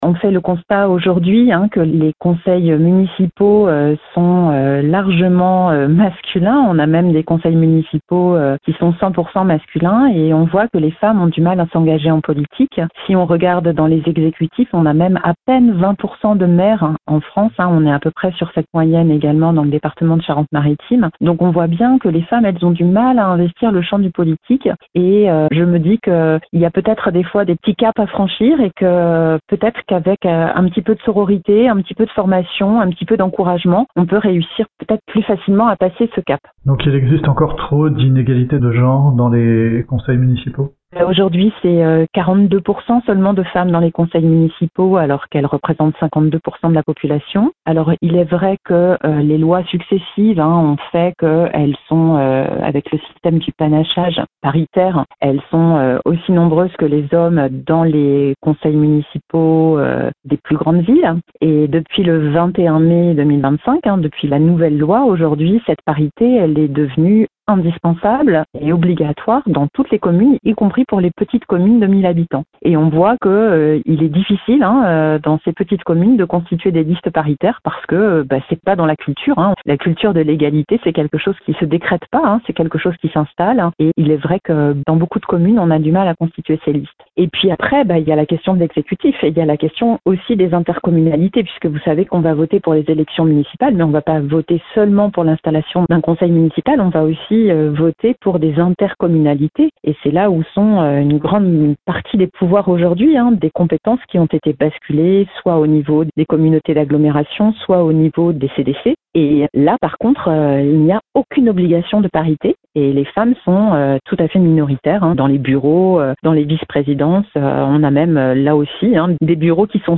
On l’écoute :